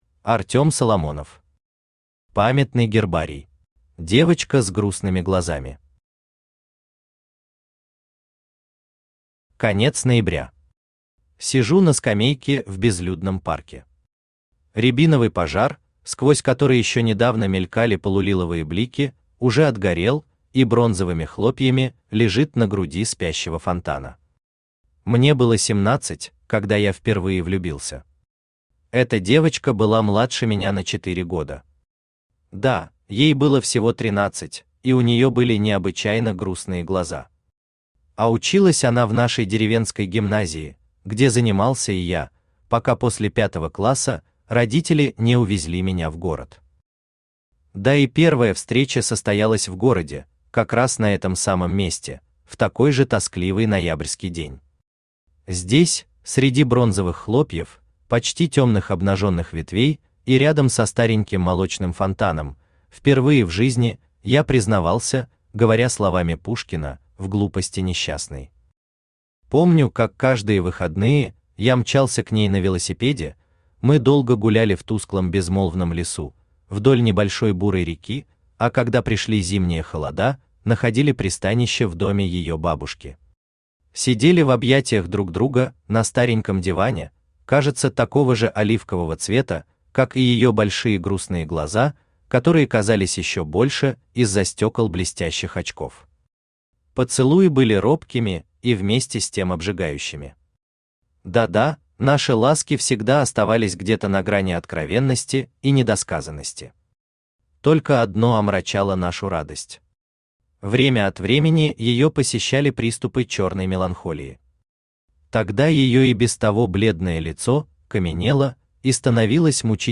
Аудиокнига Памятный гербарий | Библиотека аудиокниг
Aудиокнига Памятный гербарий Автор Артём Соломонов Читает аудиокнигу Авточтец ЛитРес.